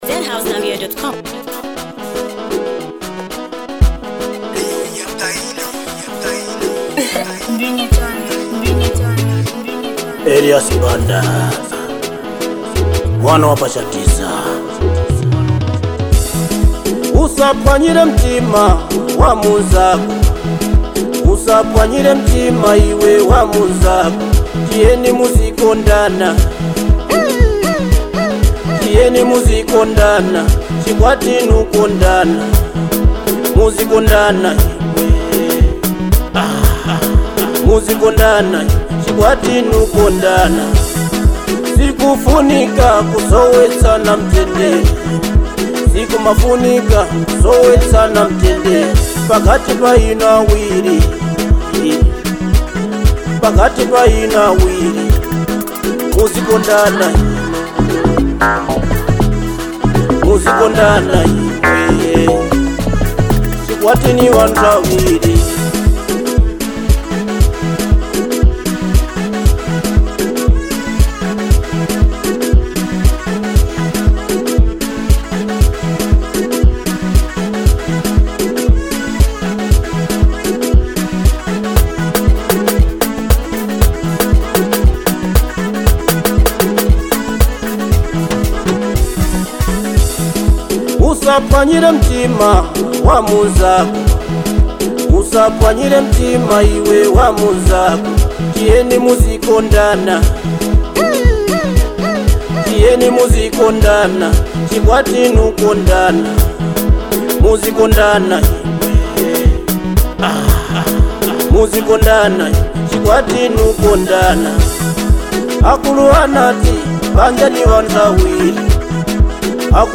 soulful vocals
a smooth production